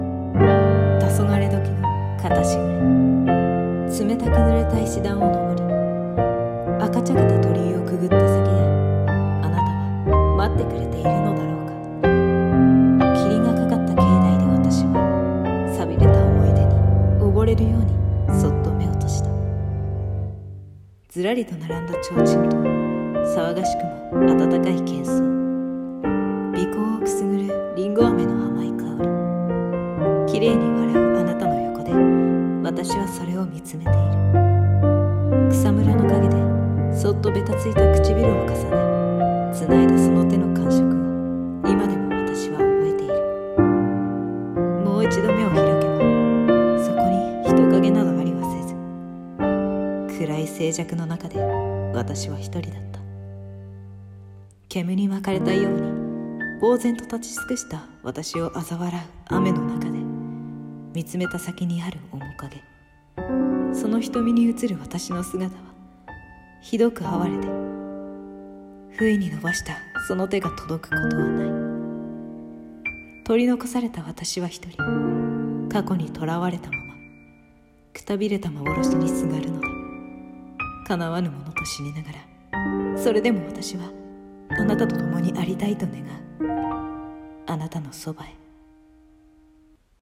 アナタノ戯ヘ【朗読